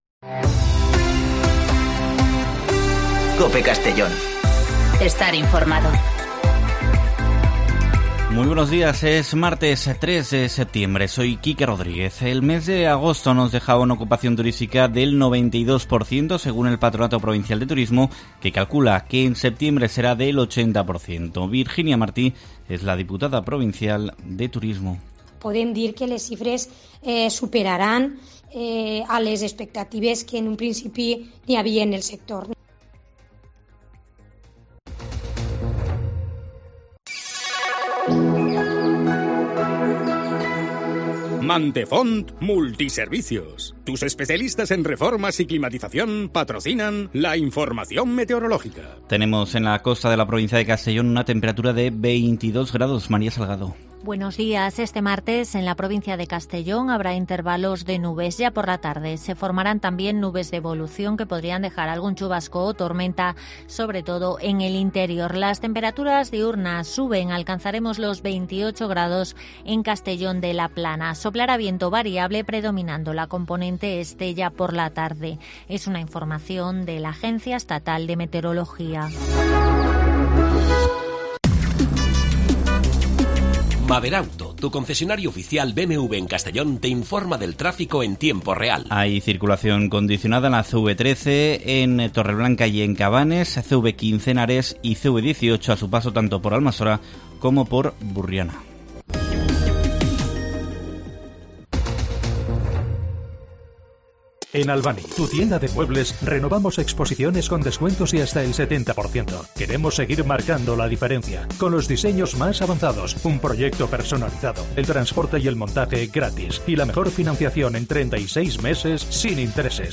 Informativo 'Herrera en COPE' Castellón (03/09/2019)